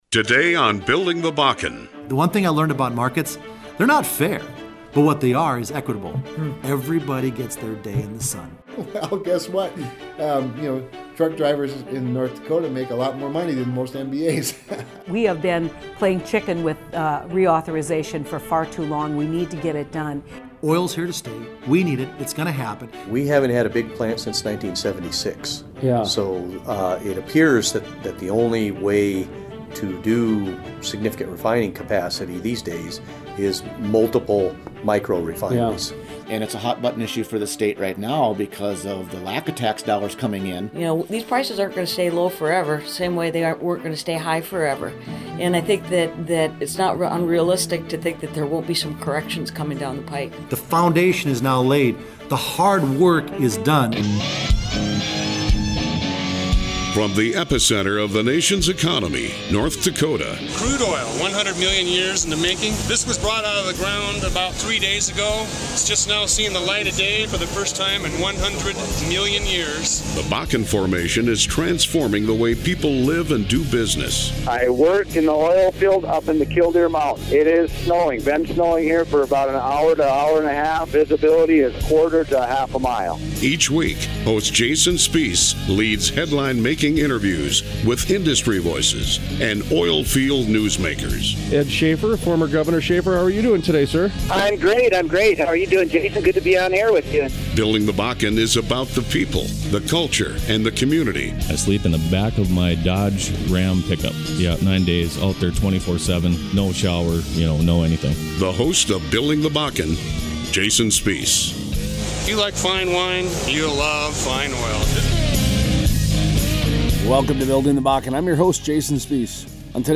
Interviews: US Senator John Hoven